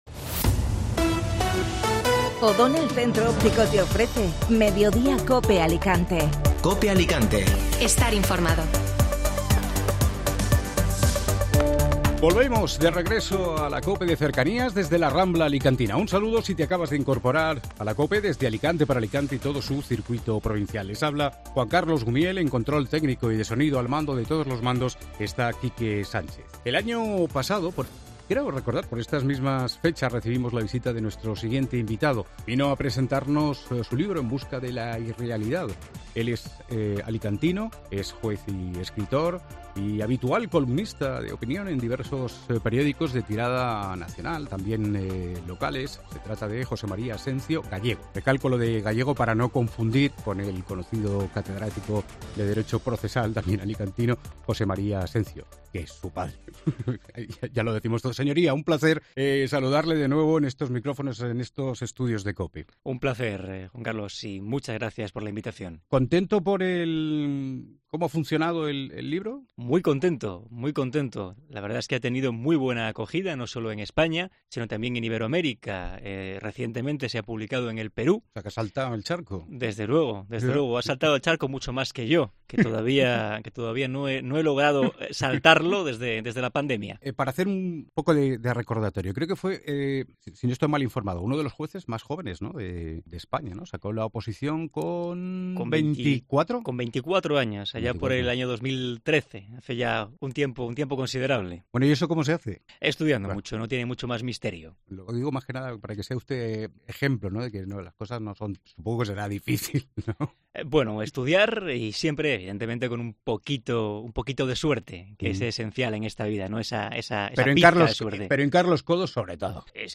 AUDIO: No te pierdas la charla con el juez y escritor alicantino José María Asencio Gallego